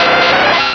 pokeemerald / sound / direct_sound_samples / cries / tentacool.aif